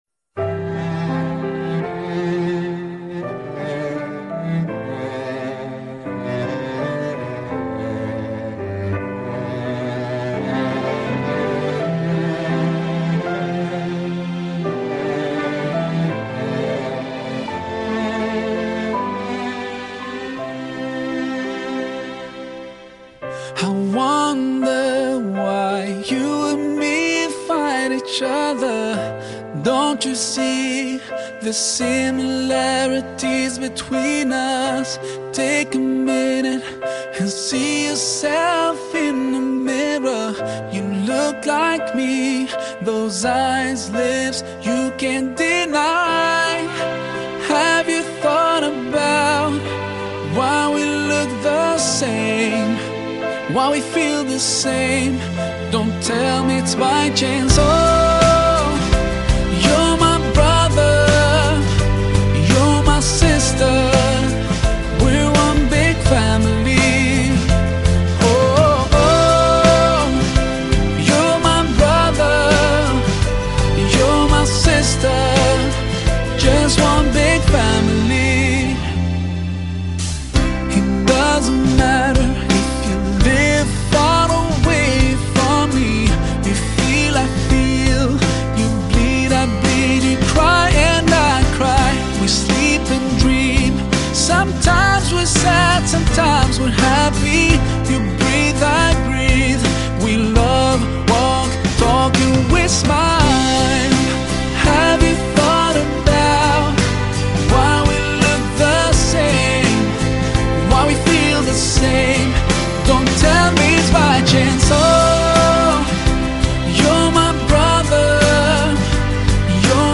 Arabic Islamic Song